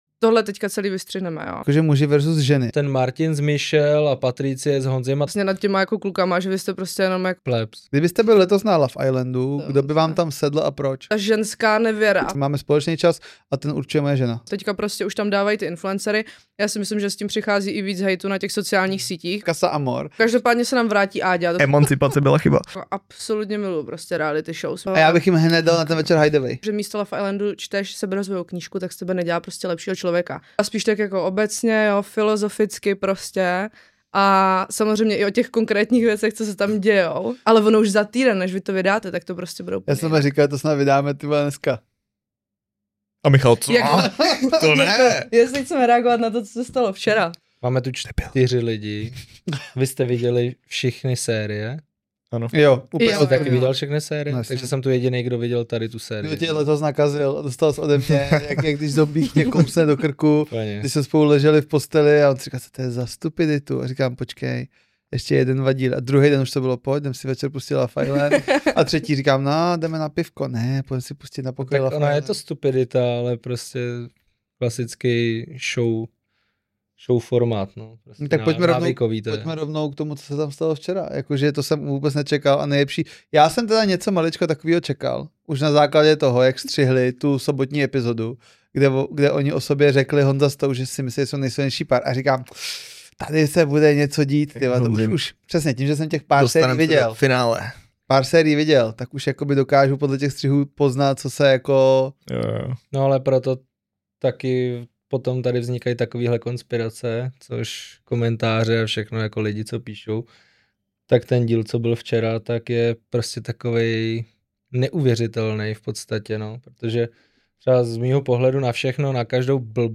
V dnešní speciální epizodě 3 KárŮ máme výjimečně ženský element